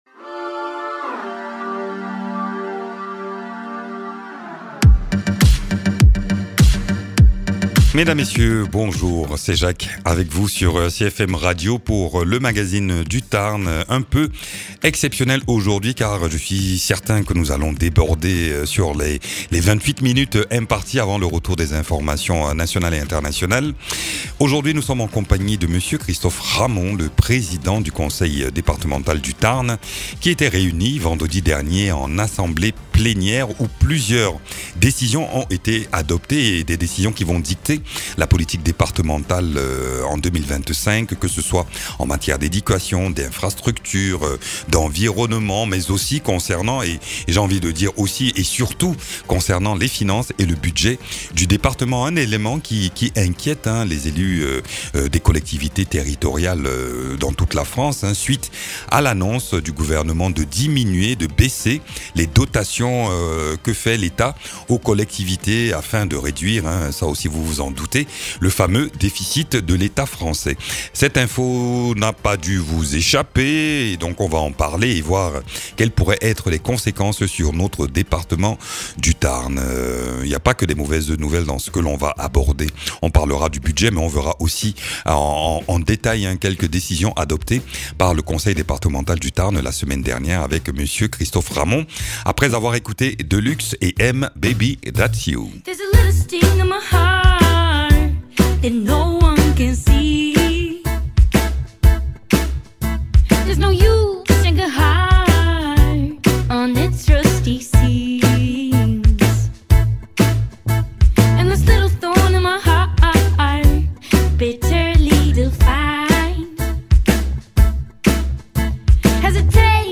Invité(s) : Christophe Ramond, président du Conseil Départemental du Tarn.